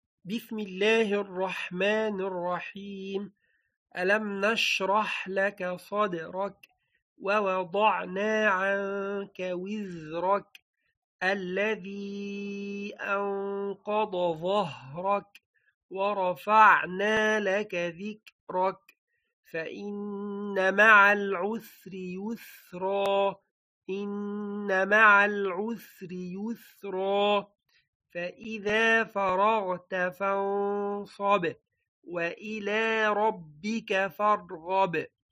• Definition: strong stoppage of the sound upon pronunciation of the letter when it carries a SAKOON.
• the quality of shiddah is to pronounce forcefully, that when pronounced, the sound of the letter will be HARD, and the sound of the letter will end off in the makhraj.